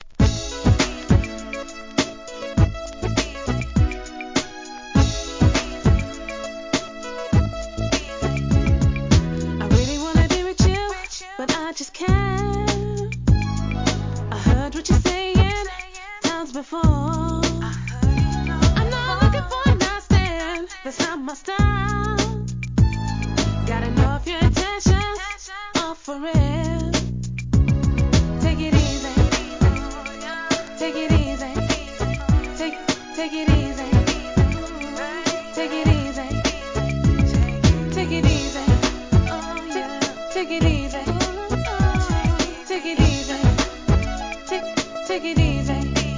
HIP HOP/R&B
マイナーR&B!